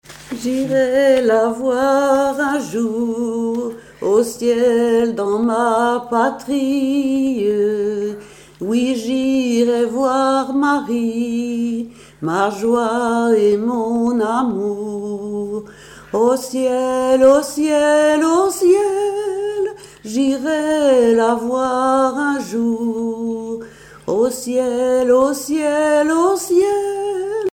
circonstance : cantique
Genre strophique
Pièce musicale inédite